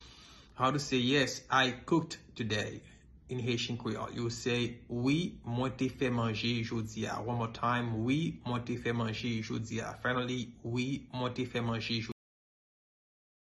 Pronunciation:
Yes-I-cooked-today-in-Haitian-Creole-Wi-mwen-te-fe-manje-jodi-a-pronunciation.mp3